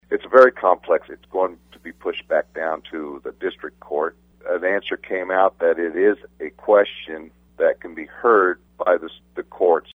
State Representative John Doll says in skimming the decision two things stood out.